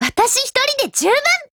贡献 ） 协议：Copyright，其他分类： 分类:少女前线:UMP9 、 分类:语音 您不可以覆盖此文件。